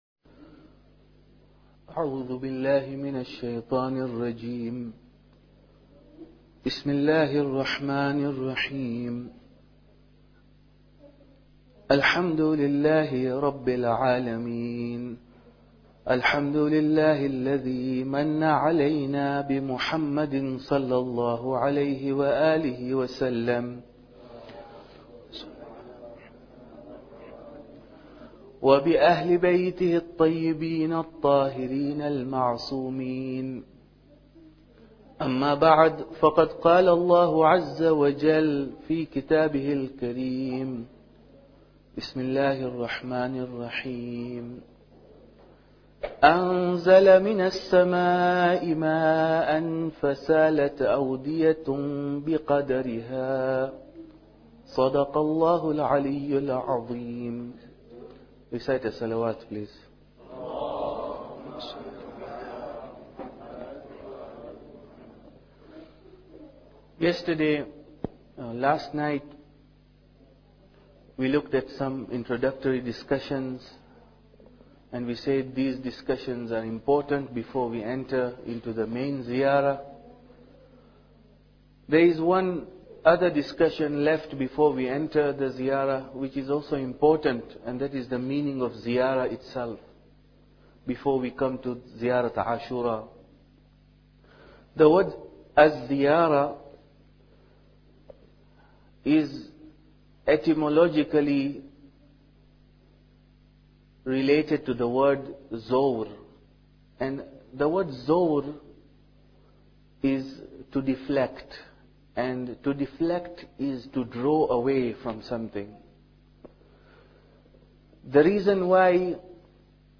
Muharram Lecture 2